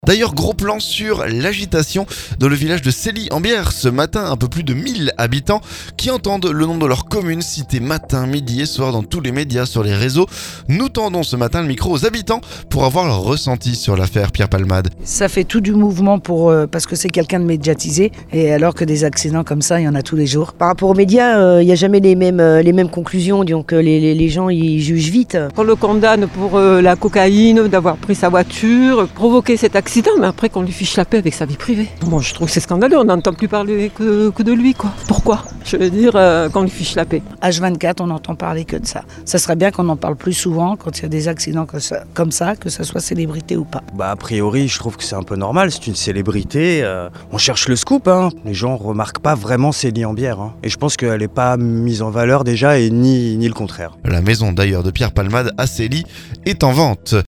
Nous tendons le micro aux habitants pour avoir leur ressenti sur l'affaire Pierre Palmade, qui a d'ailleurs mis sa maison en vente.